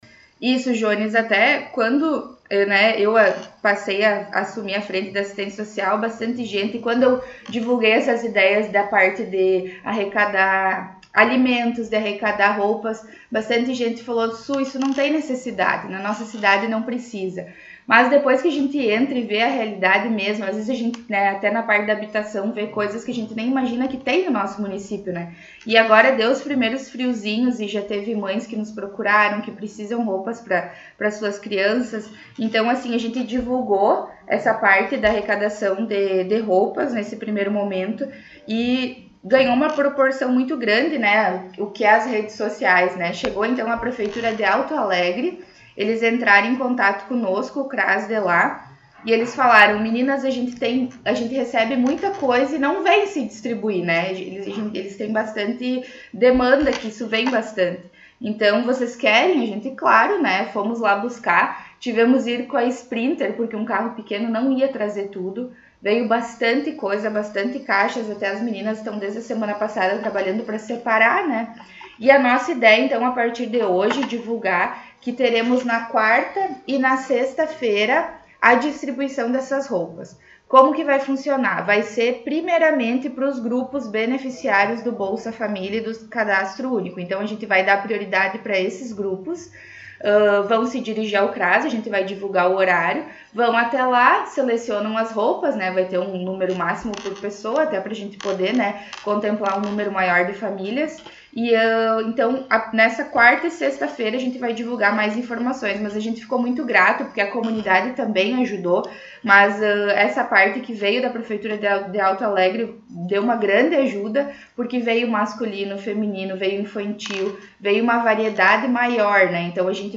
Secretária Municipal, Suelen Castro, concedeu entrevista